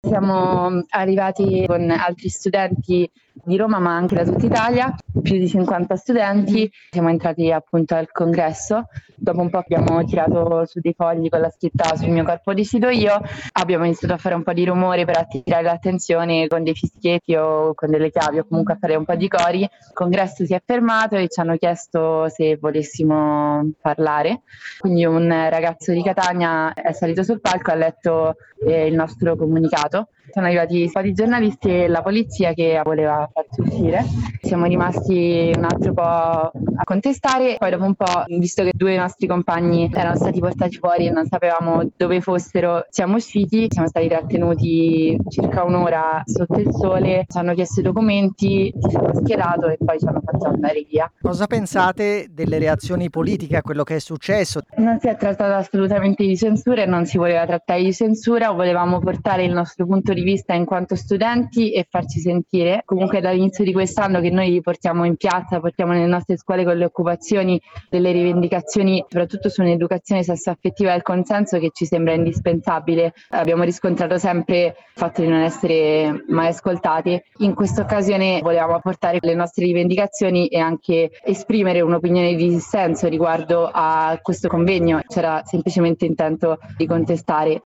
Ai nostri microfoni una studentessa delle superiori che ha partecipato alla contestazione: